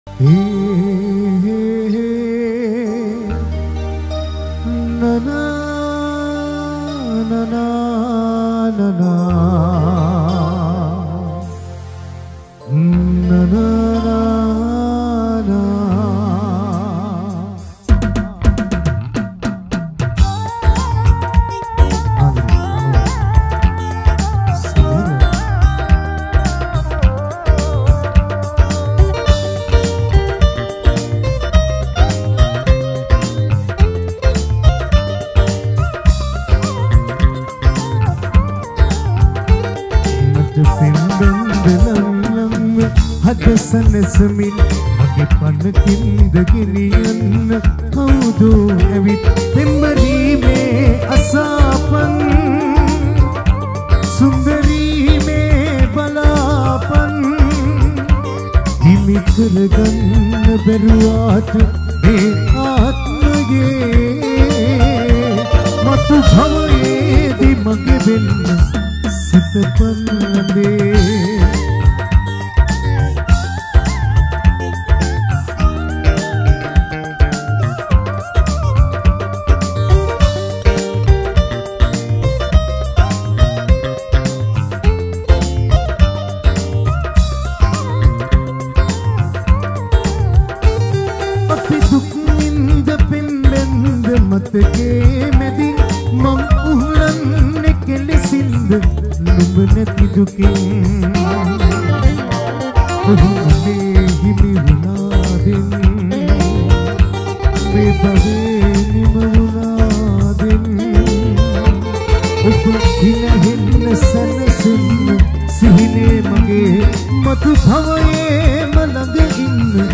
Releted Files Of Sinhala Live Show Nonstop